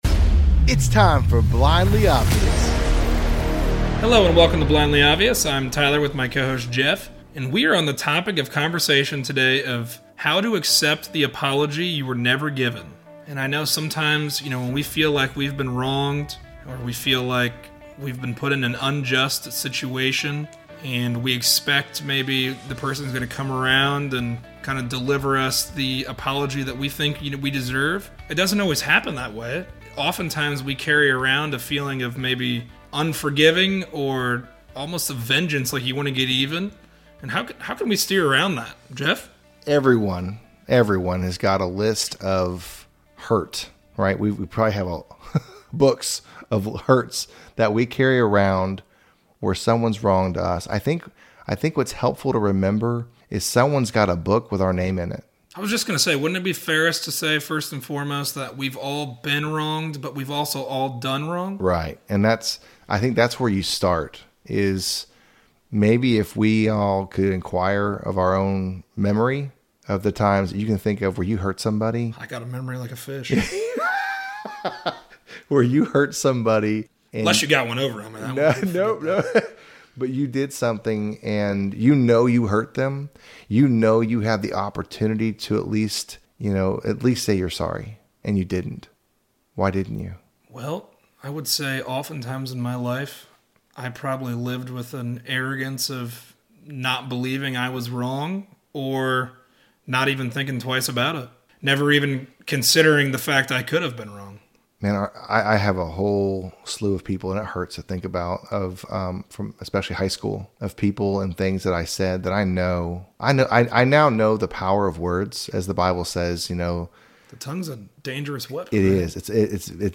A conversation on accepting that apology you never got. When harboring the feelings of unjust happenings towards us, is it harming us or the person we feel owes us the apology?